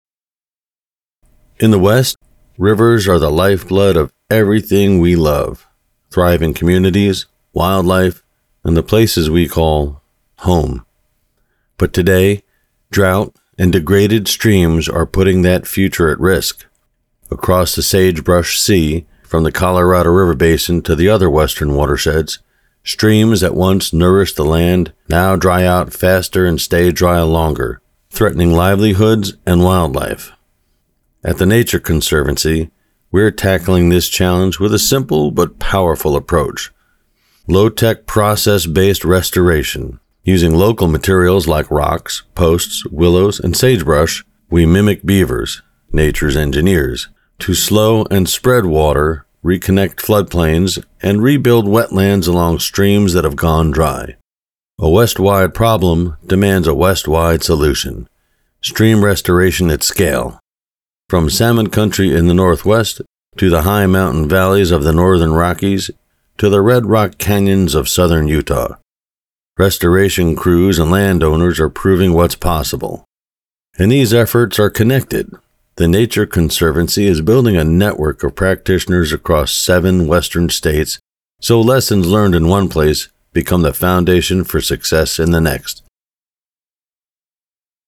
Senior